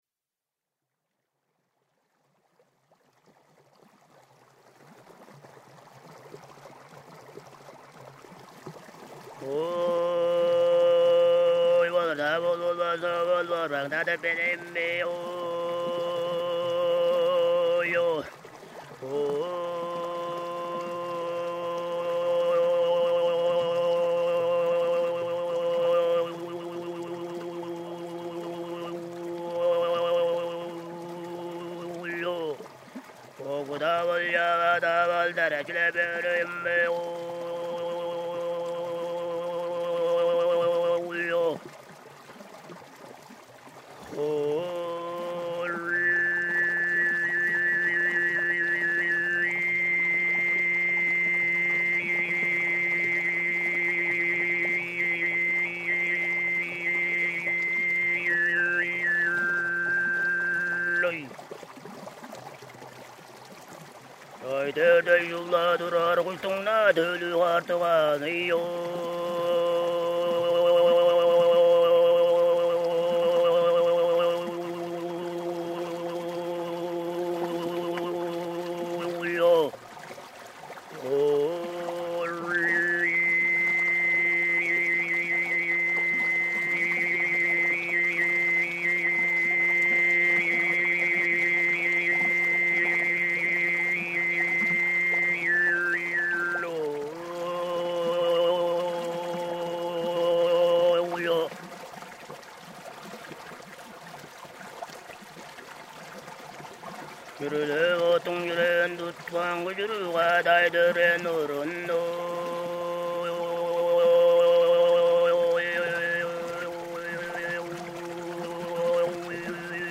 throat singing by stream. From Where Rivers and Mountains Sing: Sound, Music, and Nomadism in Tuva and Beyond.
07-Borbangnadyr-with-Stream-Water.mp3